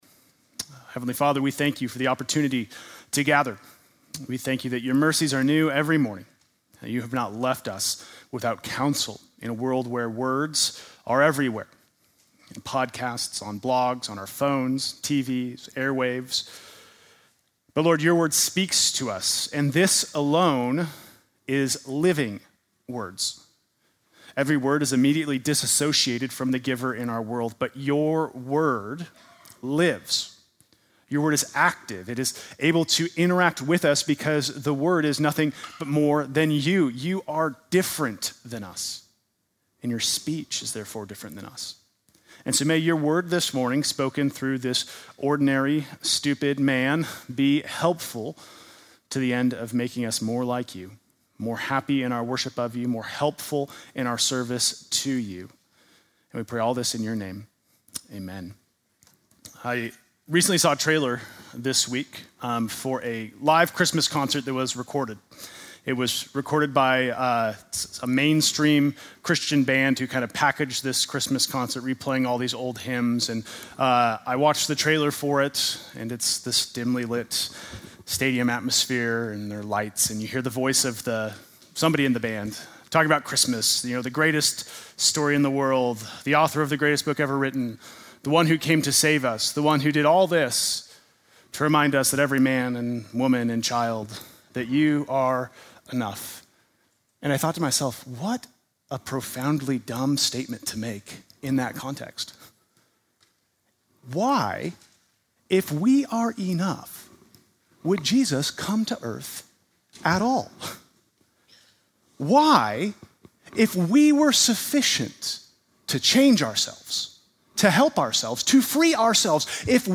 Sunday morning message December 29